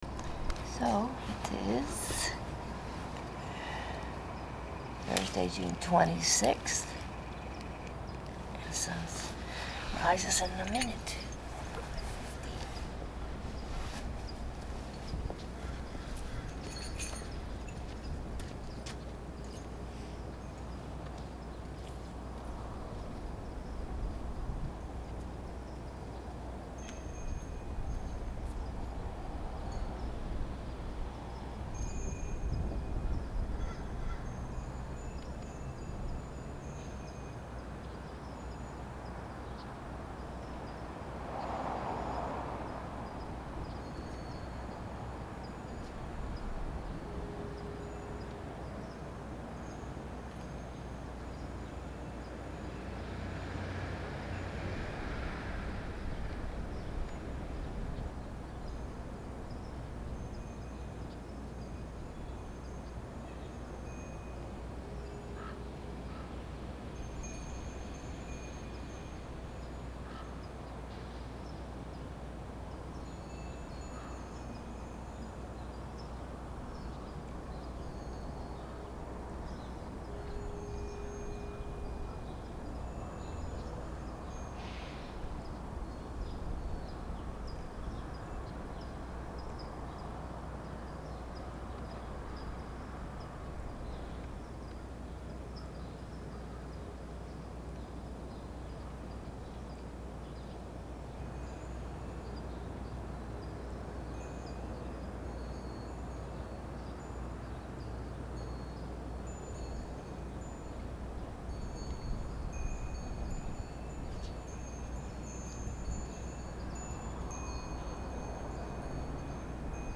Find the bells if and when you can, locate them in the space by listening to how they sound in relation to the other sounds.
6/26 5:50 AM, San Francisco. Hazy Mornings From Distant Fires